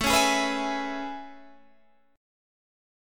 AM9 chord